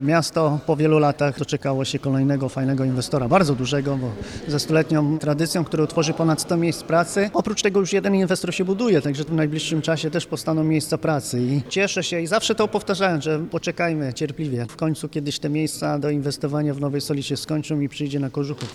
– To jest wielki rozwój dla miasta – powiedział burmistrz Paweł Jagasek: